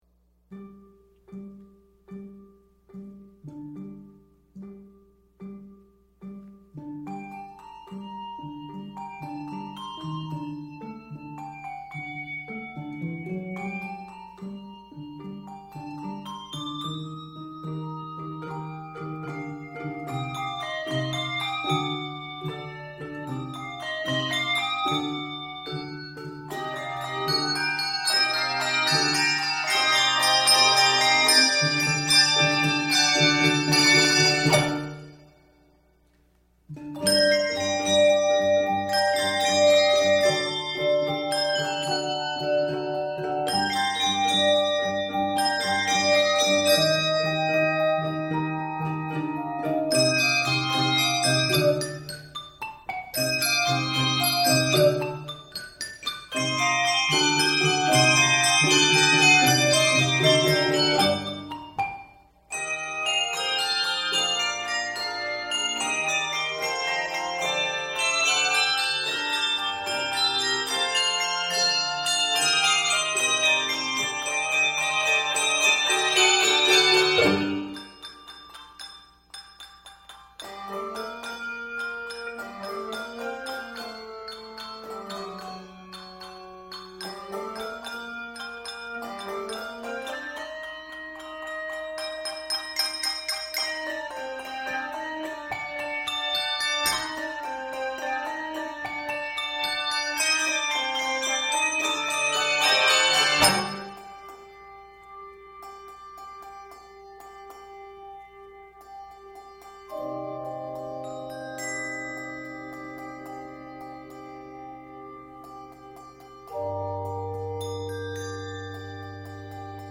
N/A Octaves: 3-6 Level